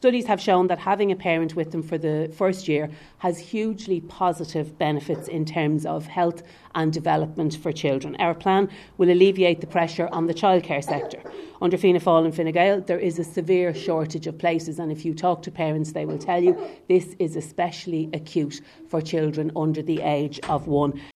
Sinn Féin spokesperson on Workers’ Rights, Louise O’Reilly says it would take pressure off the childcare sector.